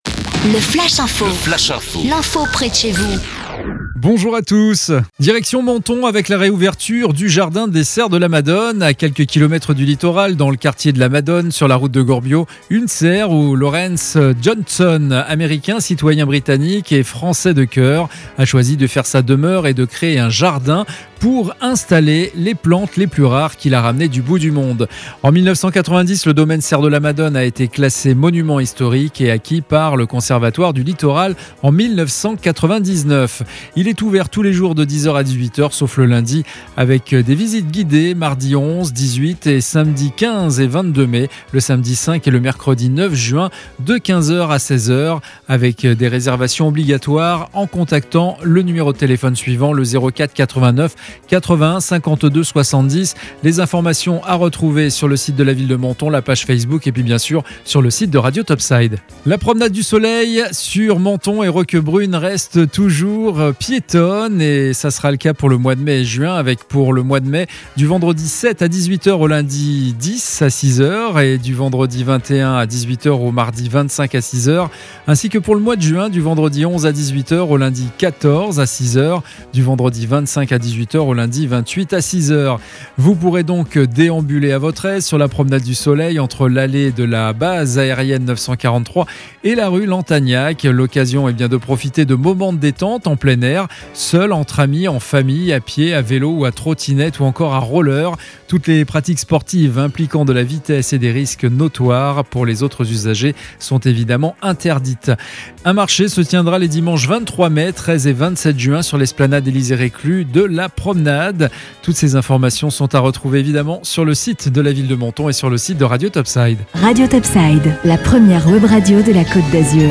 Menton Actu - Le flash info du jeudi 6 mai 2021